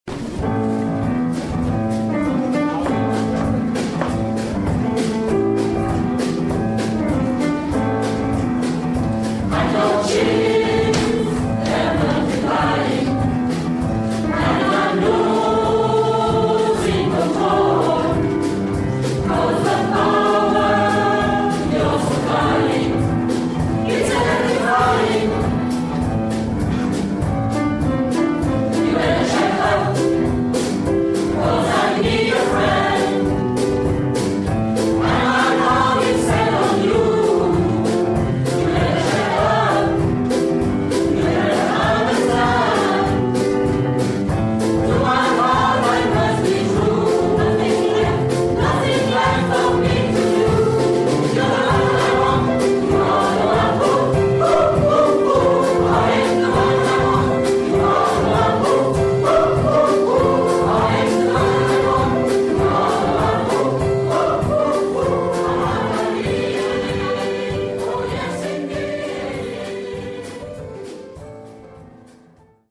Chorale Vivavoce